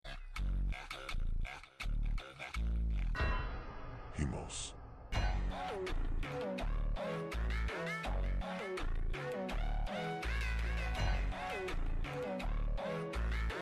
2 Goats Sound Effects Free Download